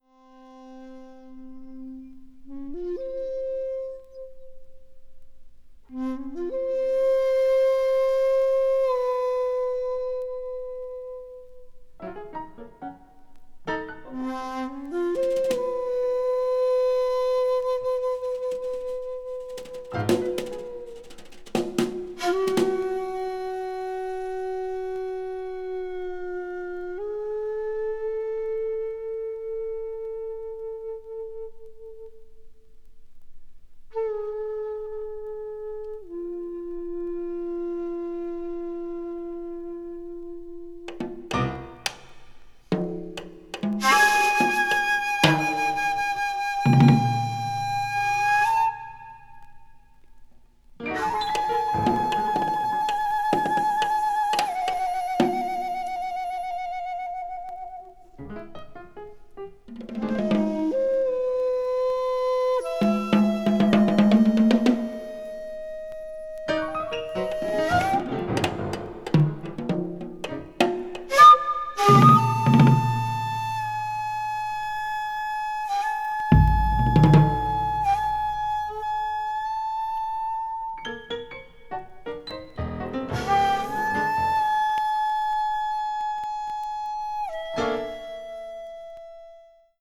bamboo flute player
the great Japanese drummer and percussionist
one of Japan's leading jazz pianists